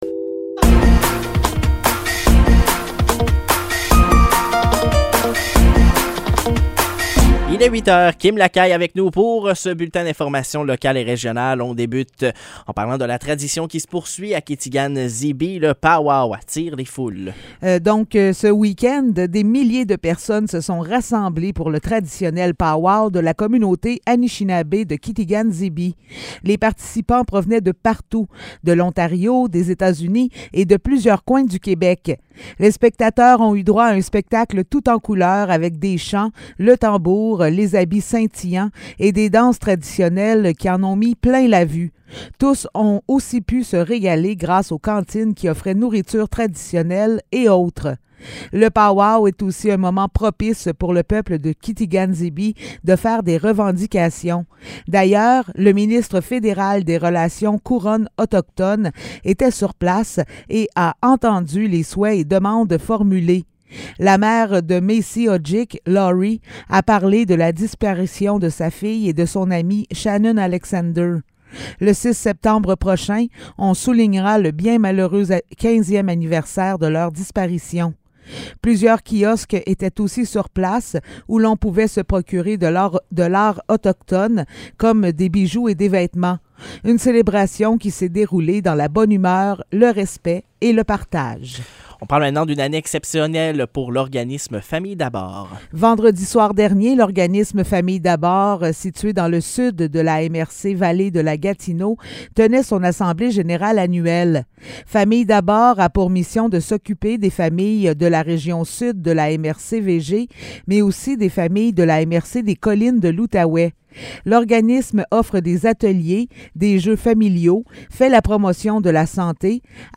Nouvelles locales - 5 juin 2023 - 8 h